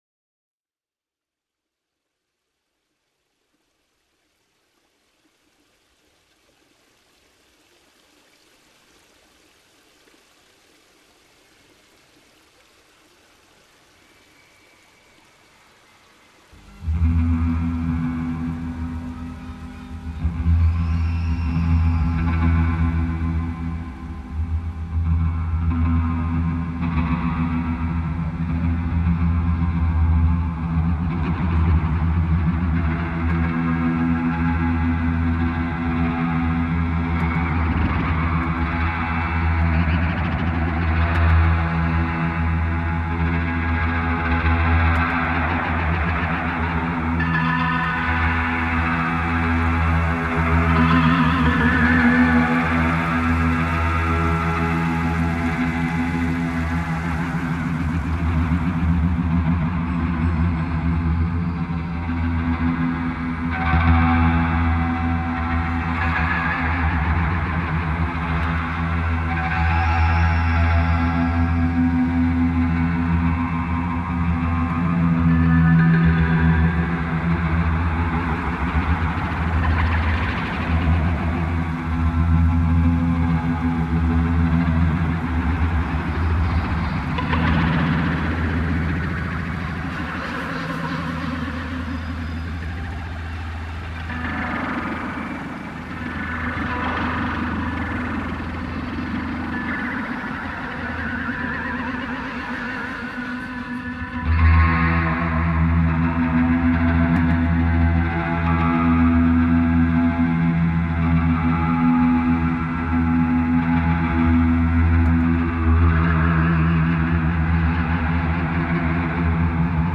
Très bonnes musiques d’ambiances, cela lance le voyage de belle manière, bravo.
« Aux frontières de la vrume » et « terres interdites » sont inquiétants à souhait, je m’y vois vraiment !